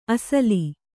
♪ asali